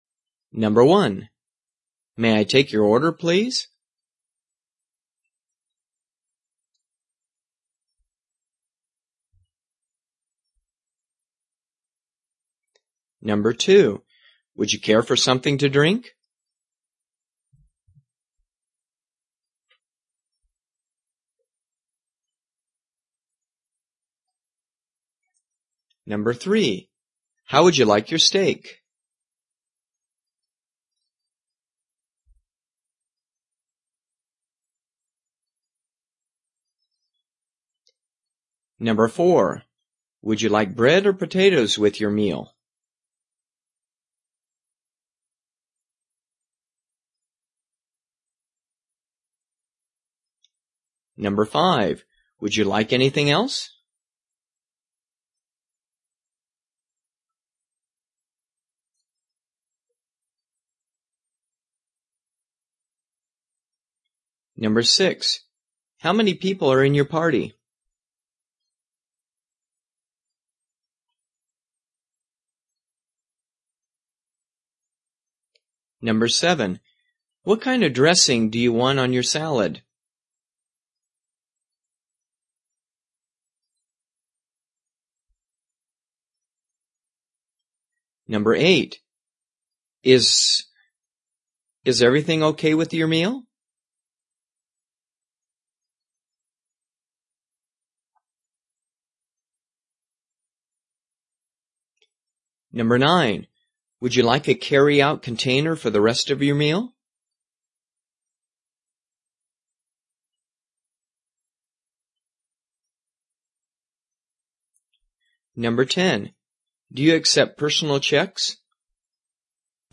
初级英语听力听写测试题 11：餐厅点菜英语(mp3下载)